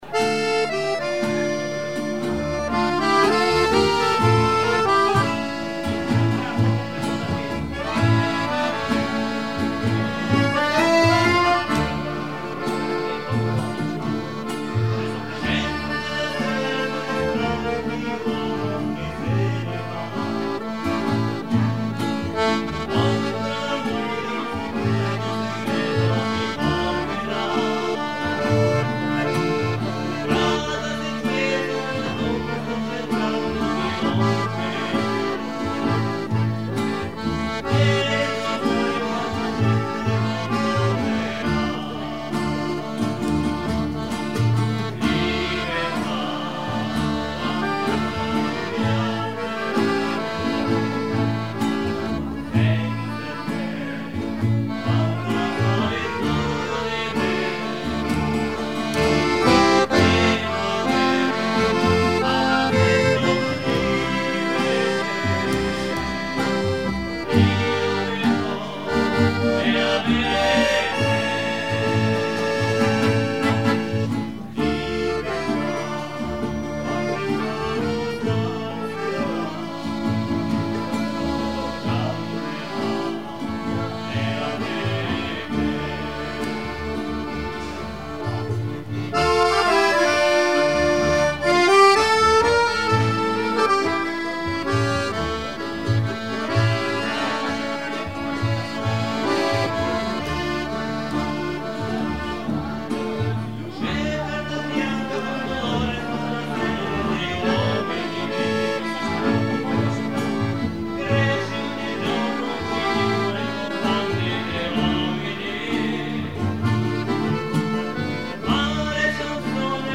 Mélodie su un rythme de tango
Pièce musicale inédite